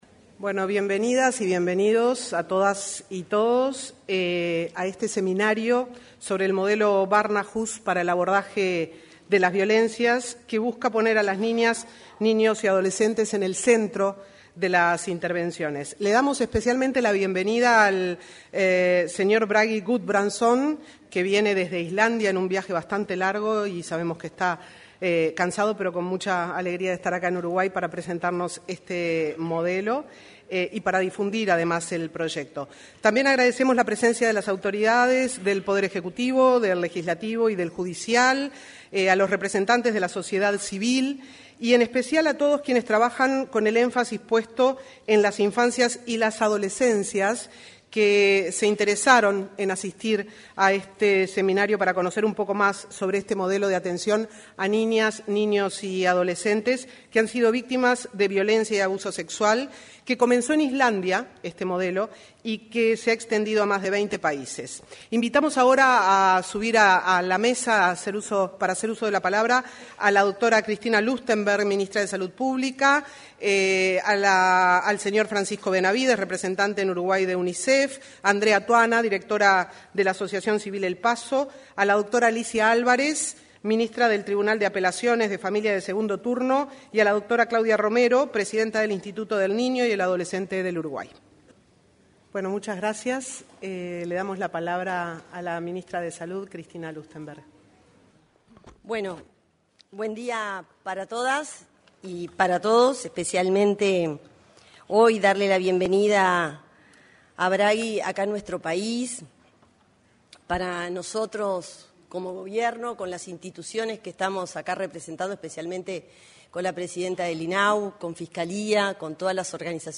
Se realizó, en el auditorio de la Torre Ejecutiva, el seminario Modelo Barnahus para el Abordaje de las Violencias.